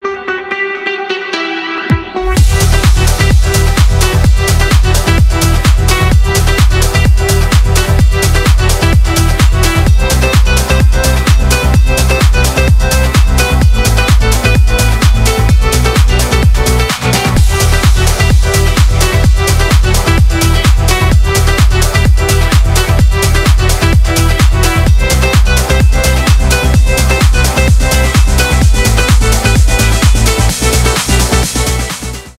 без слов
progressive house , танцевальные , клубные